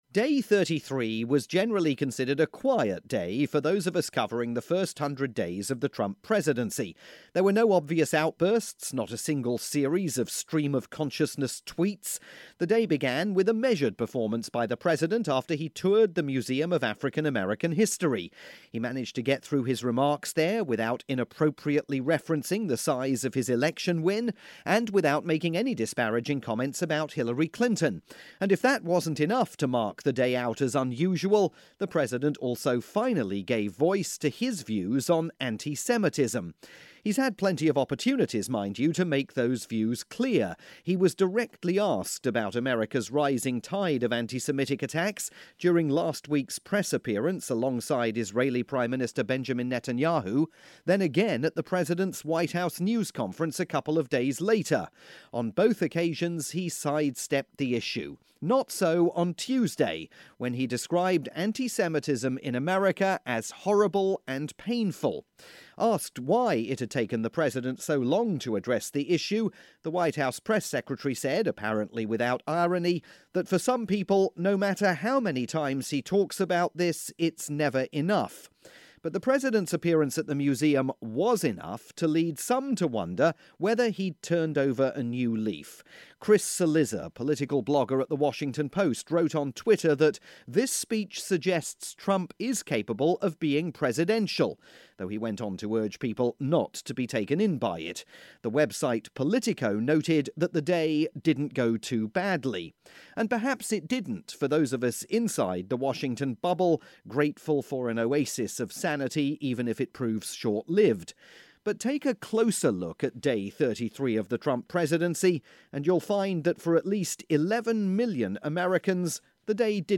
Airing on radio stations worldwide.